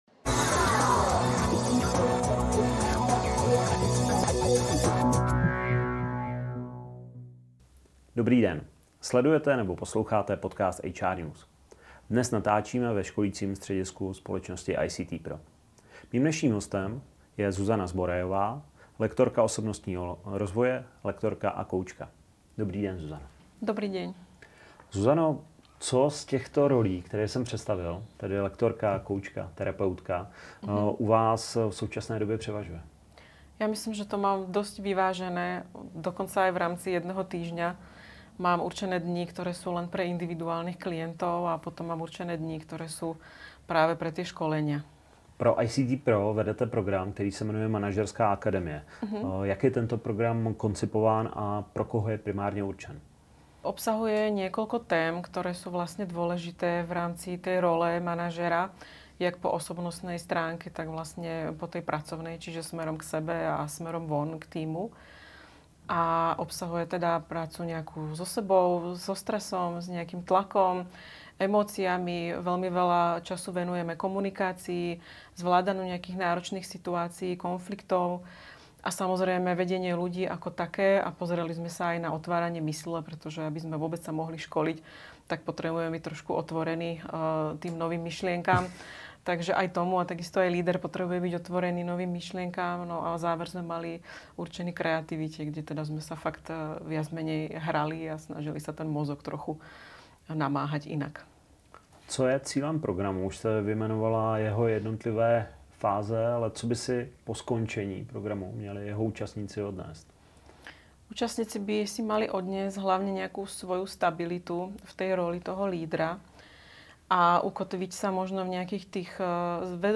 V rozhovoru sdílí, jak propojuje koučování a terapii s výukou manažerských dovedností. Diskutuje o důležitosti emoční inteligence a schopnosti pracovat s emocemi v leadershipu. Věnuje se tématům Time managementu, asertivity a rovnováhy mezi osobním a profesním životem.